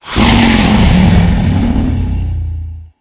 growl4.ogg